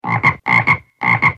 Другие рингтоны по запросу: | Теги: жаба, Лягушка